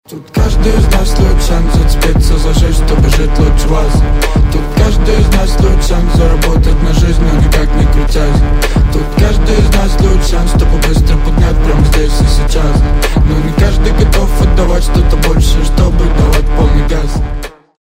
• Качество: 320, Stereo
басы